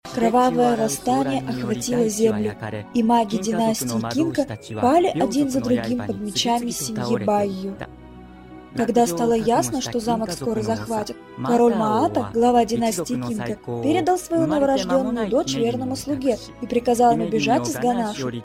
Но голос не плохой кажеться. и техничиски вроде тоже неплохо.